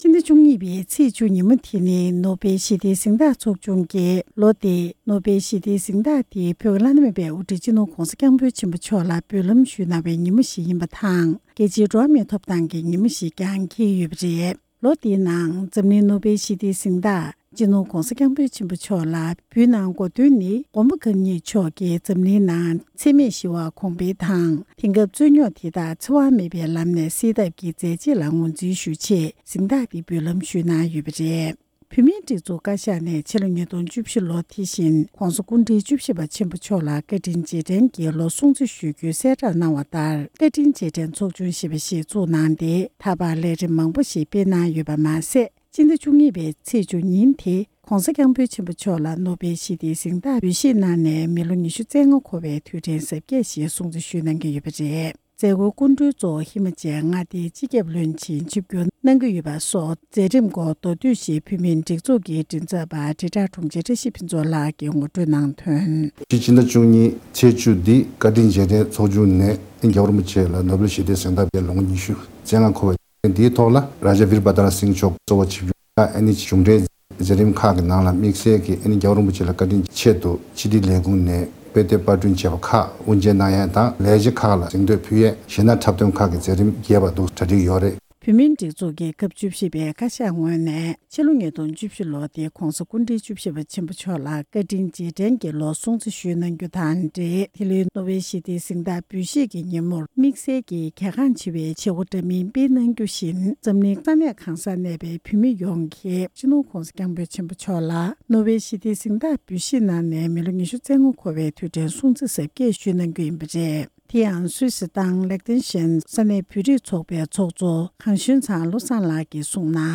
༄༅། །གསར་འགྱུར་དང་འབྲེལ་བའི་ལས་རིམ་ནང་།